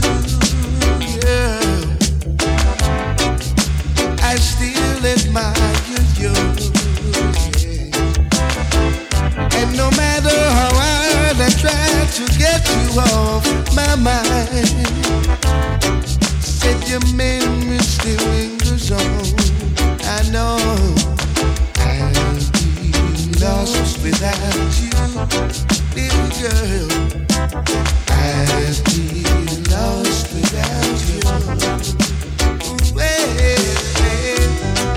Off-beat гитары и расслабленный ритм
Reggae
Жанр: Регги